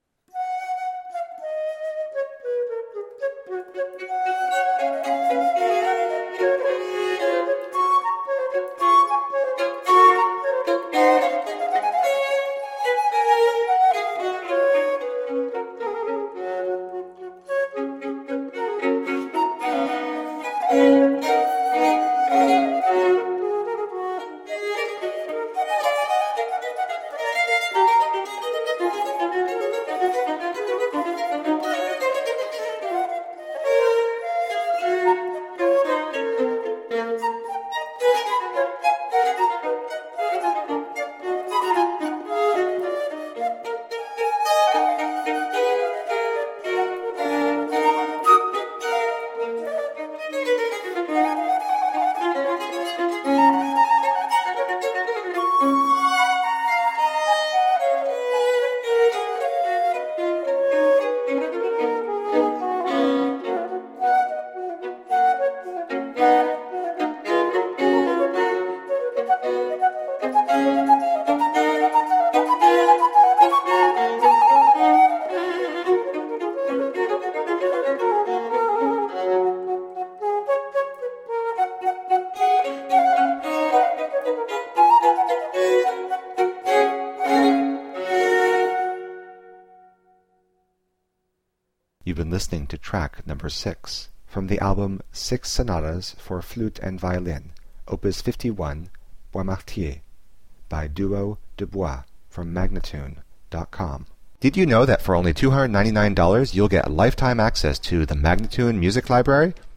Exquisite chamber music.